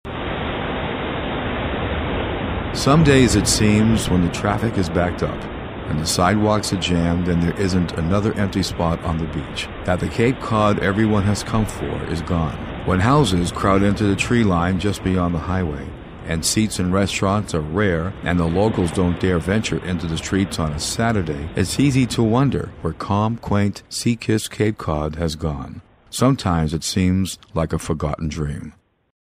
Voiceover Demo